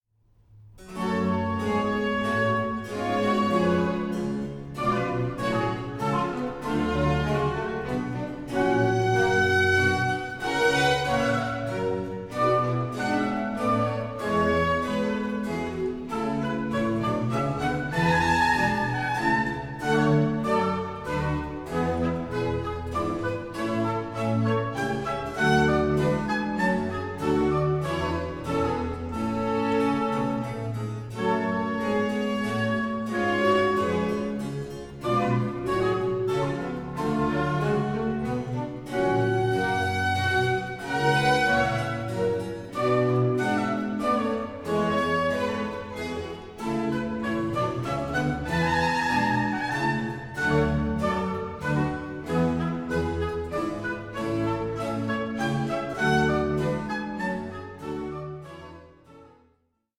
Organ Concerto No.8 in A major, Op.7 No.2